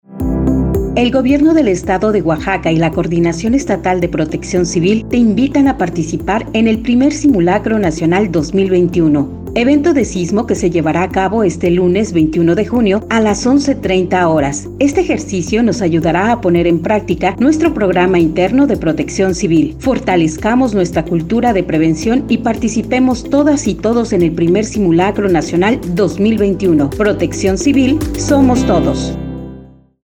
PROMO-CEPCO-SIMULACRO-30-SEG.mp3